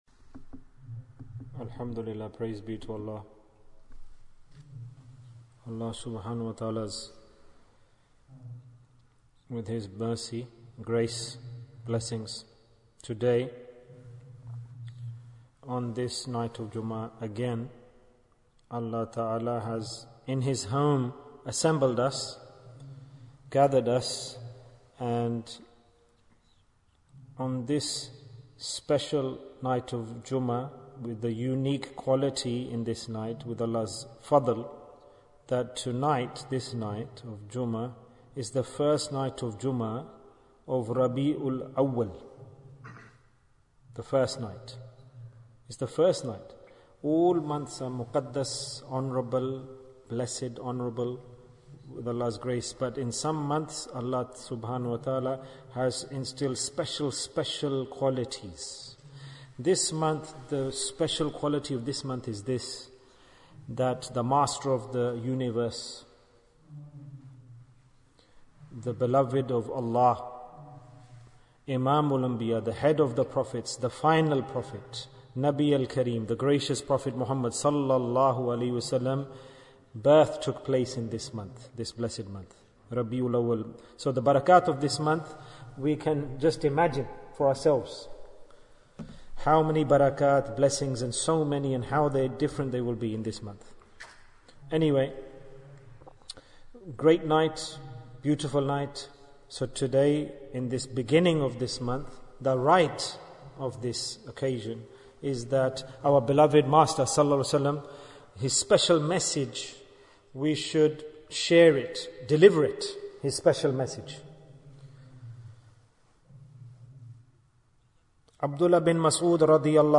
The Message of Rabbi-ul-Awwal Bayan, 65 minutes7th October, 2021